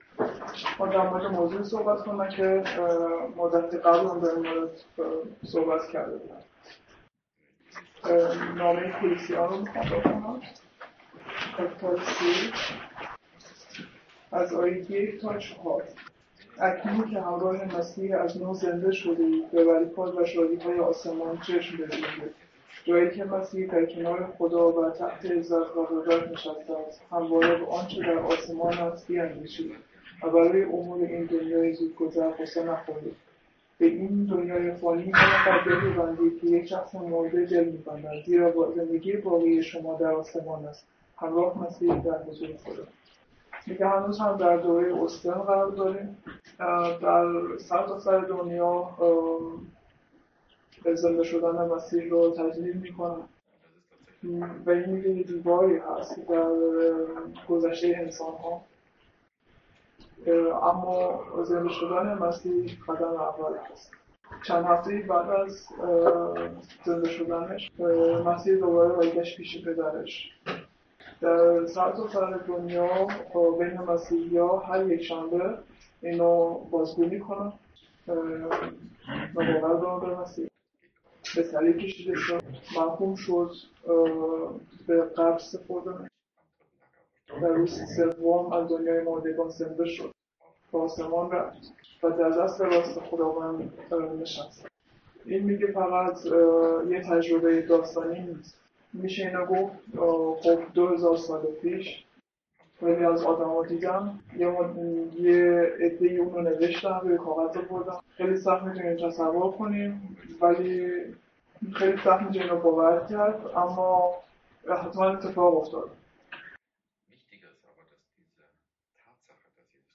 Übersetzung auf Persisch der Predigt Kolosser 3, 1-4 auf persisch